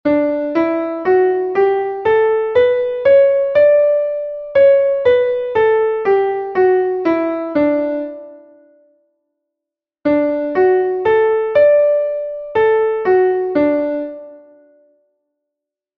Entoación a capella
Escala e arpexio:
escala_arpegio_re_M.mp3